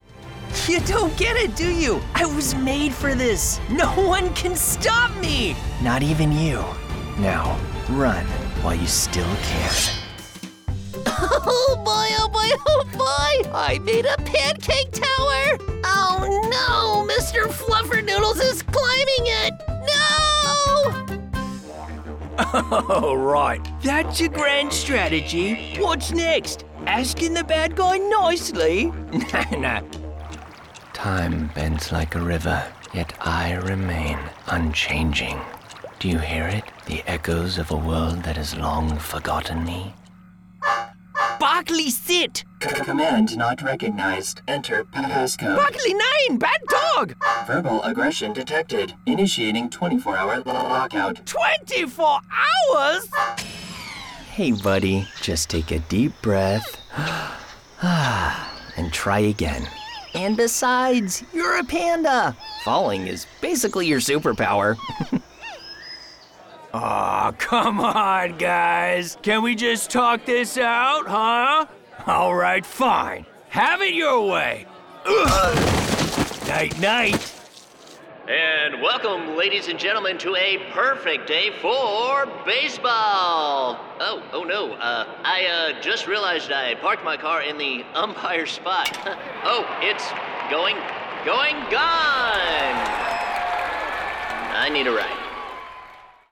Animation Demo Reel
British, Australian, German, Southern
Young Adult